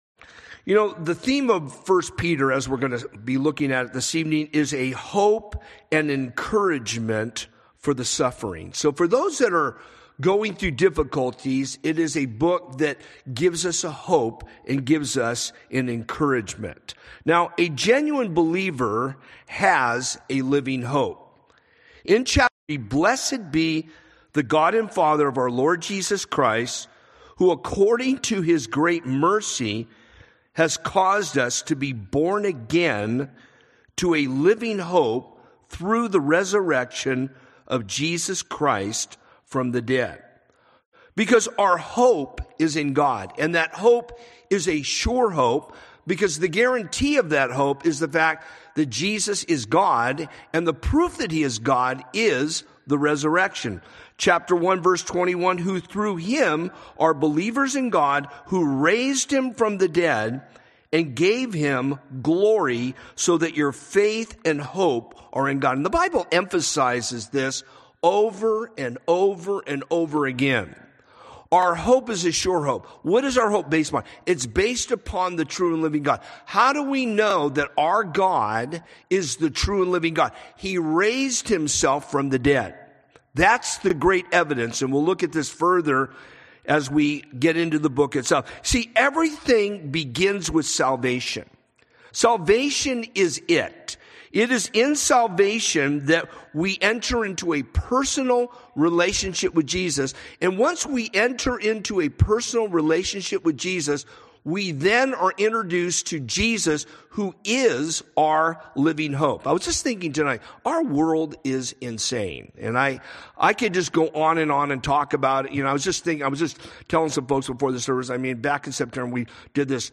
A message from the series "Wednesday - 19:00."